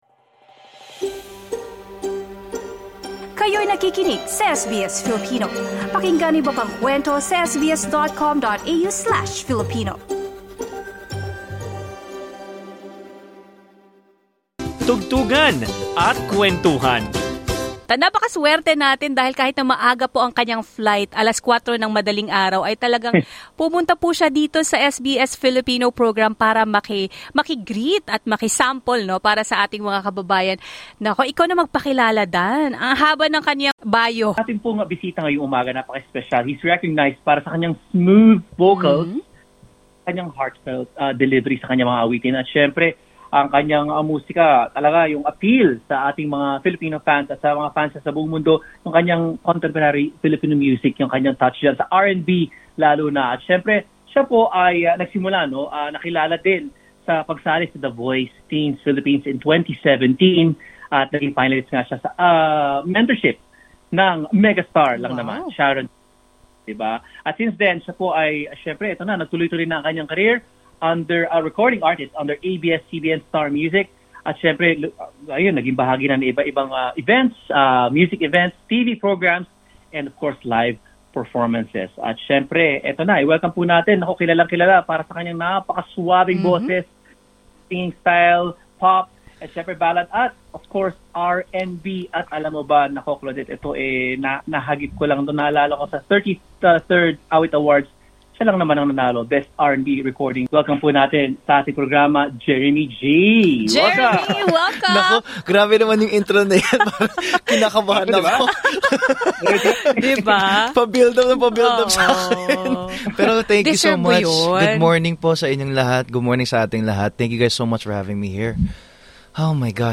Kapamilya star Jeremy G visits SBS Melbourne Credit: SBS Filipino